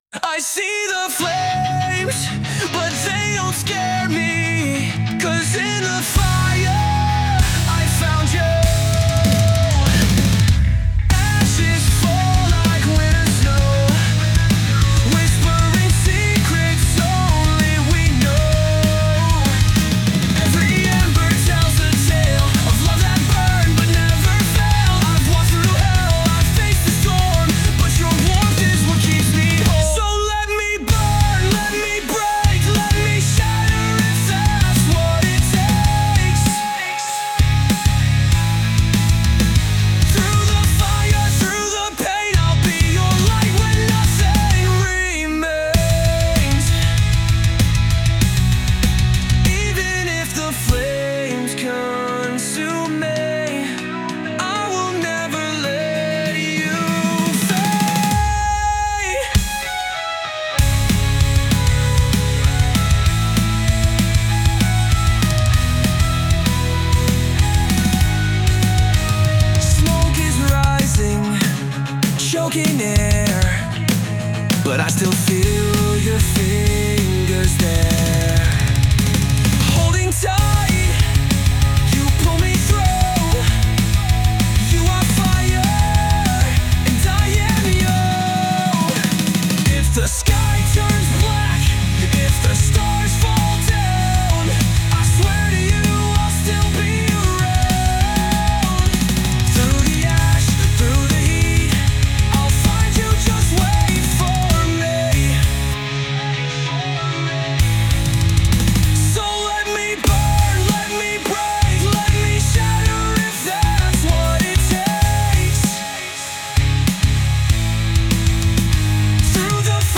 Genre: Alternative Metal/Metalcore/Deathcore